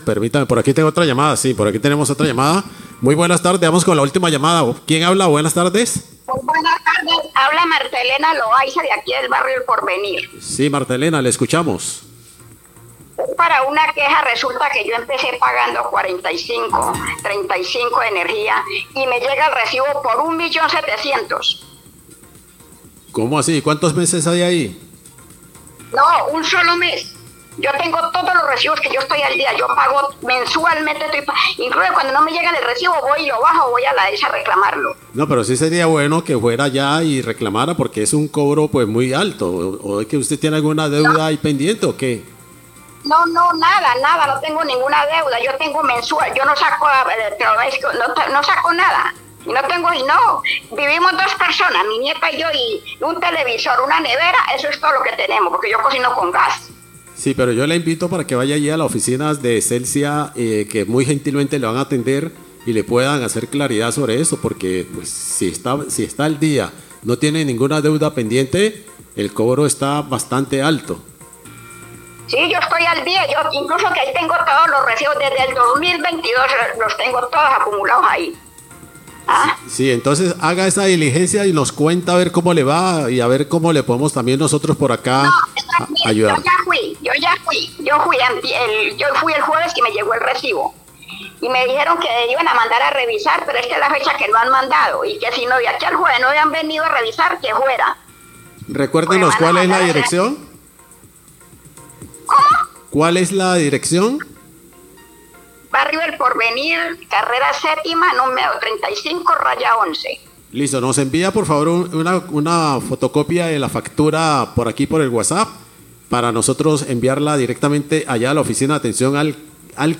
Oyente barrio Porvenir se queja por aumento en la facturación de energía
Radio